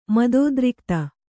♪ madōdrikta